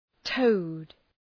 Προφορά
{təʋd}